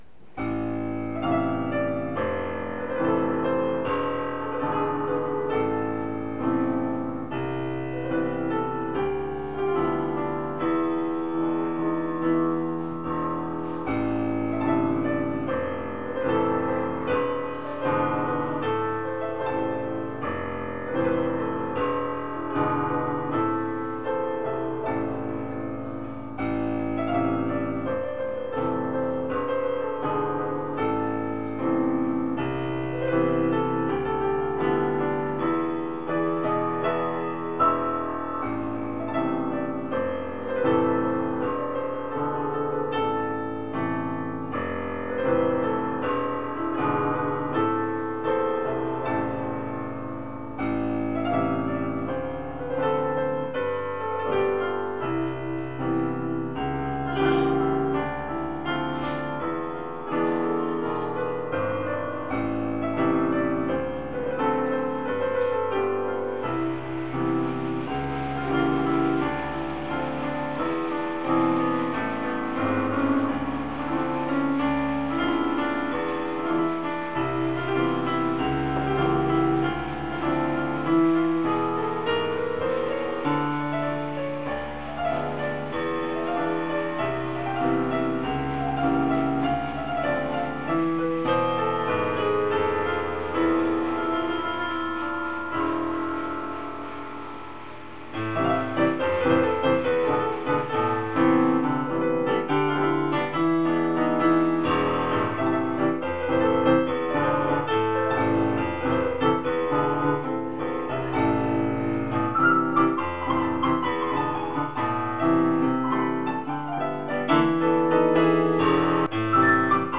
הקטע האיטי הוא הליכת חימום לפני הריצה...